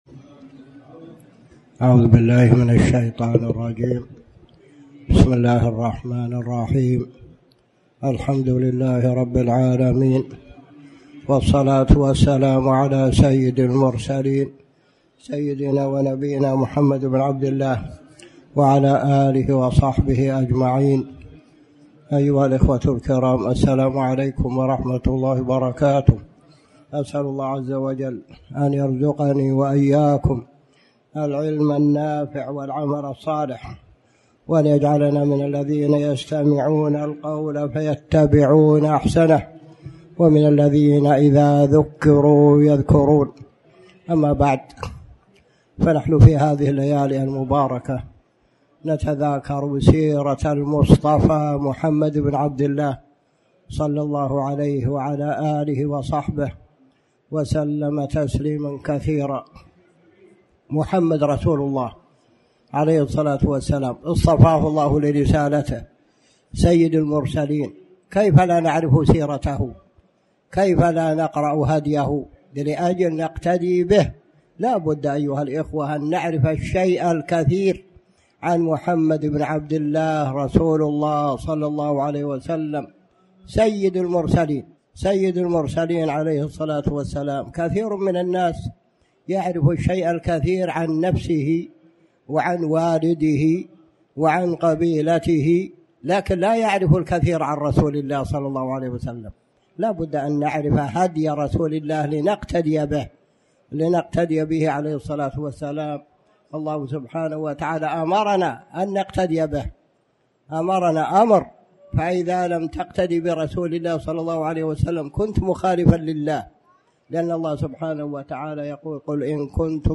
تاريخ النشر ١١ رجب ١٤٣٩ هـ المكان: المسجد الحرام الشيخ